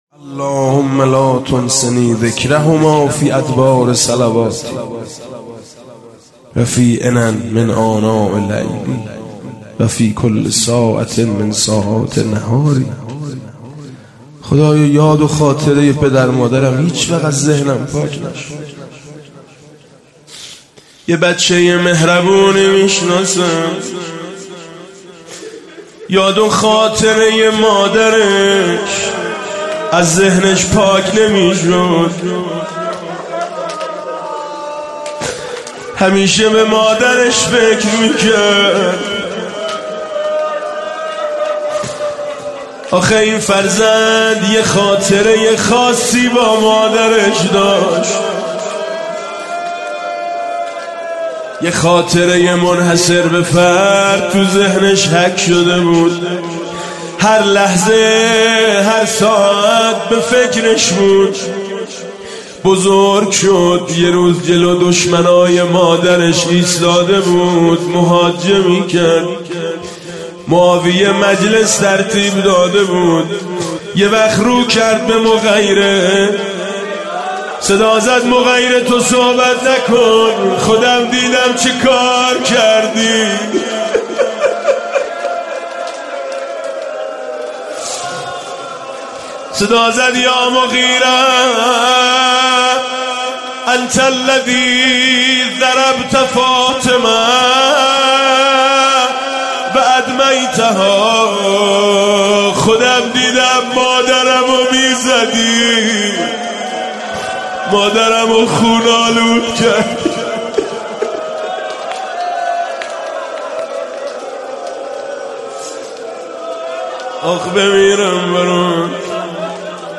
روضه امام حسن مجتبی میثم مطیعی رمضان 98
مداحی جدید حاج ميثم مطيعی شب دوم رمضان هیات میثاق با شهدا سه شنبه 17 اردیبهشت 1398